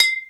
HR16B BOTTLE.wav